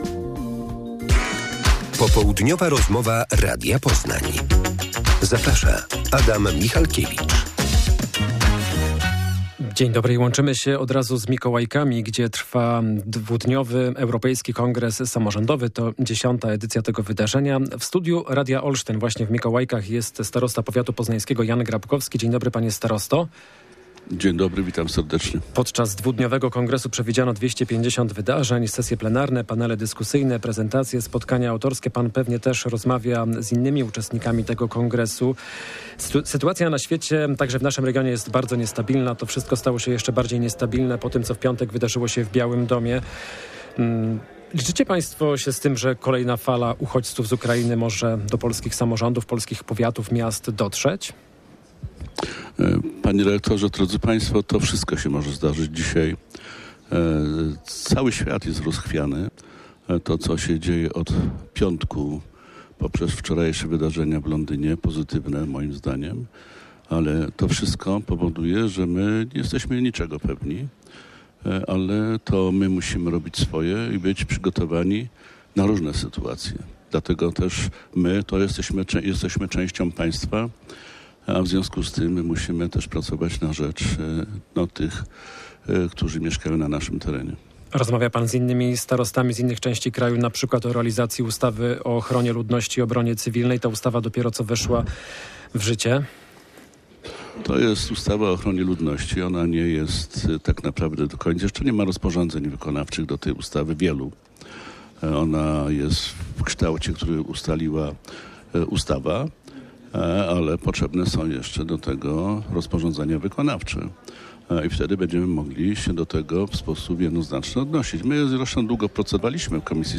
Popołudniowa rozmowa - Jan Grabkowski